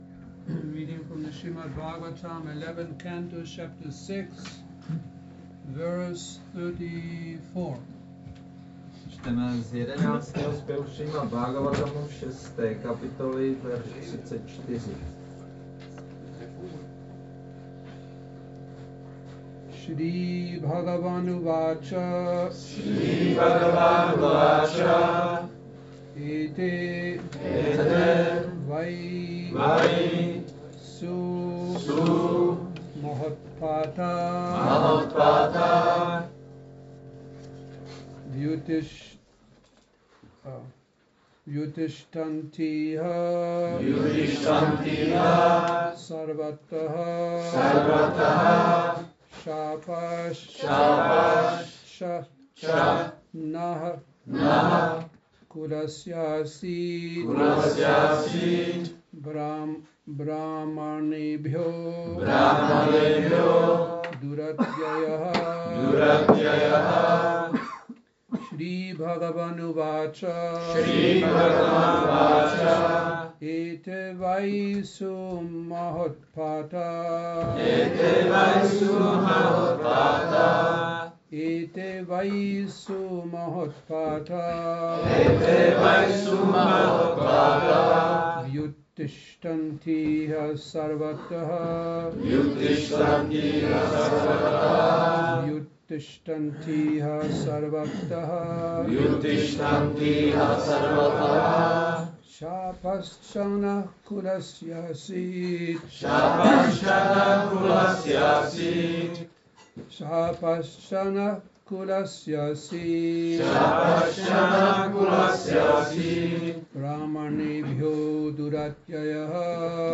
Přednáška SB-11.6.34 – Šrí Šrí Nitái Navadvípačandra mandir